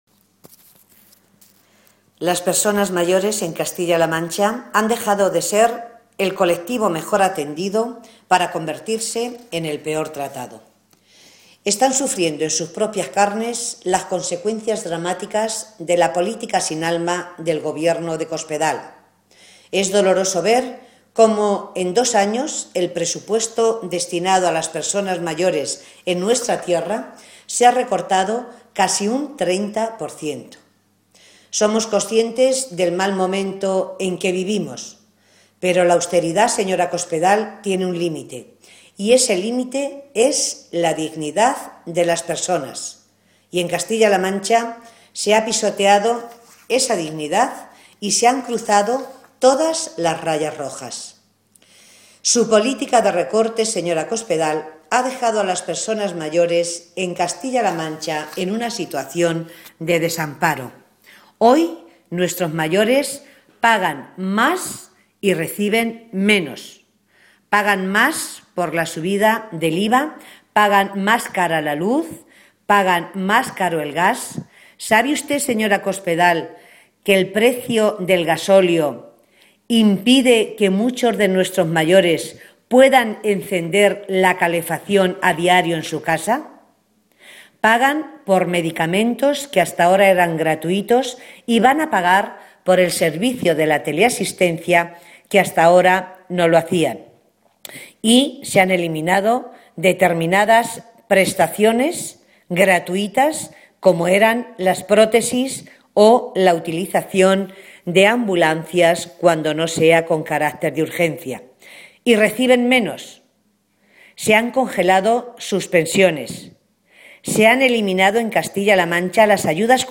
Cortes de audio de la rueda de prensa
matilde_valentin_1.mp3